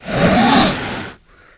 c_wserpent_hit2.wav